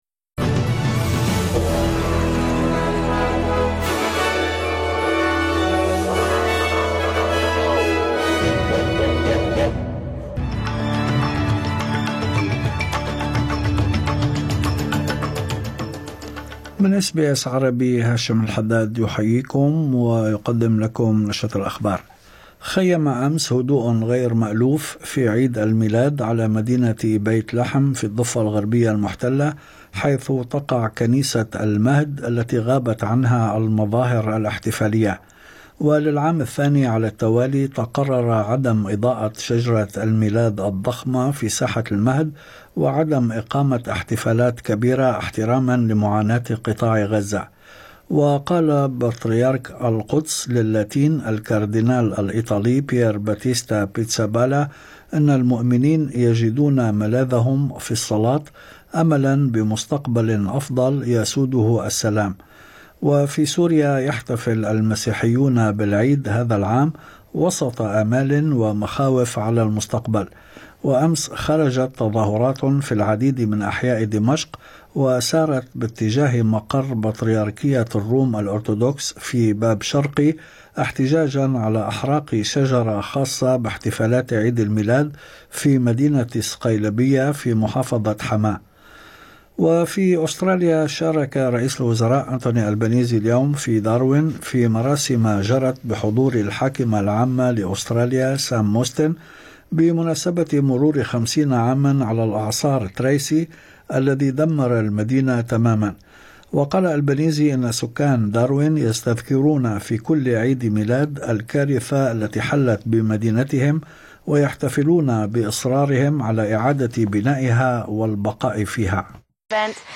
نشرة أخبار الظهيرة 25/12/2024